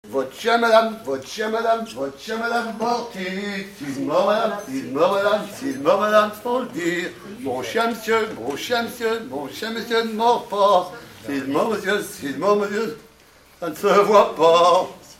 Mémoires et Patrimoines vivants - RADdO est une base de données d'archives iconographiques et sonores.
danse : mazurka
Pièce musicale inédite